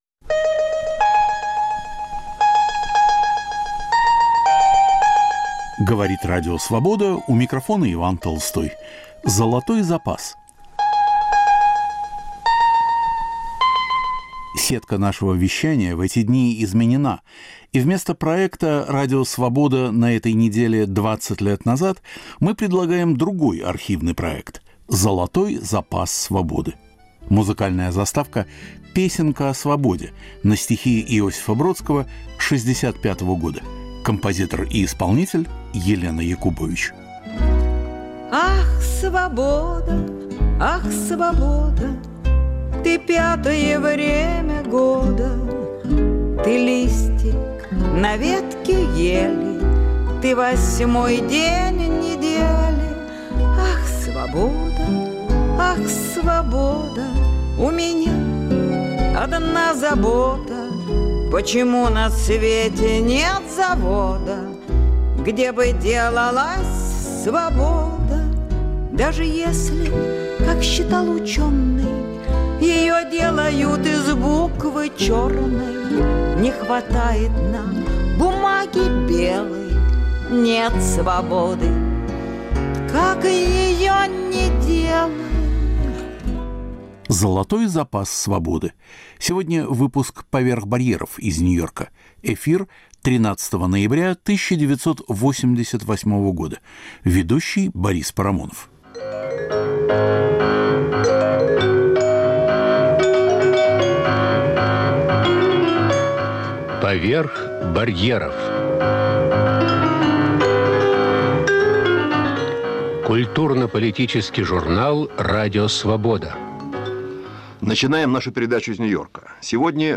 Михаил Булгаков - загадка влияния и тайна успеха. История в эпиграммах: о новом фильме Андрея Кончаловского. Ведущий Борис Парамонов.